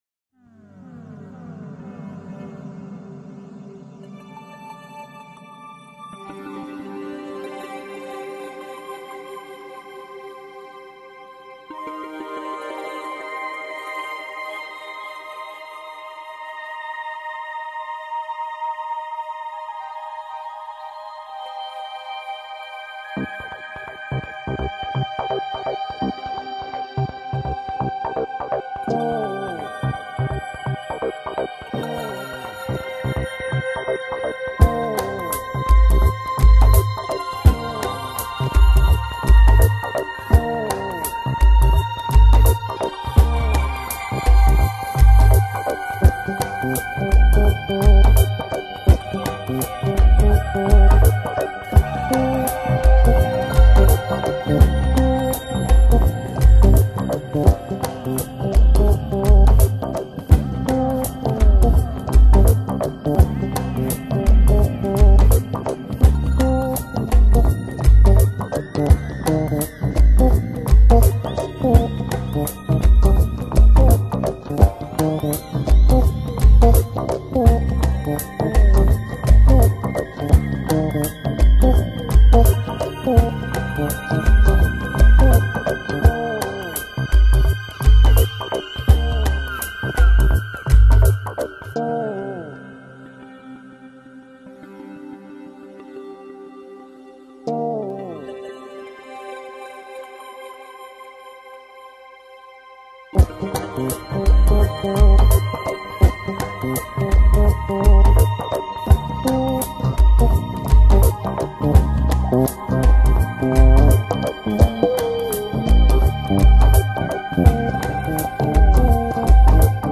音乐类型: New Age